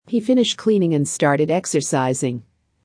【ノーマル・スピード】